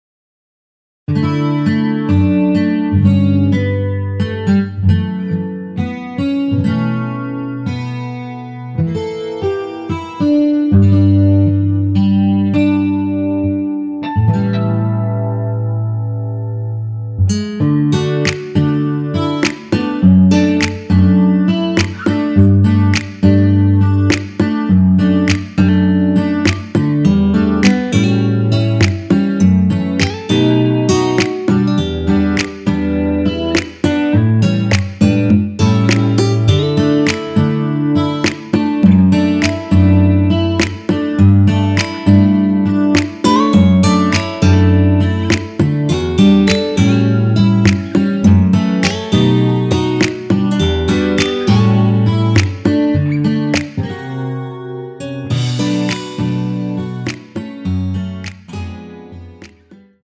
엔딩이 페이드 아웃이라 라이브 하시기 좋게 엔딩을 만들어 놓았습니다.
원키에서(-1)내린 MR입니다.
앞부분30초, 뒷부분30초씩 편집해서 올려 드리고 있습니다.
중간에 음이 끈어지고 다시 나오는 이유는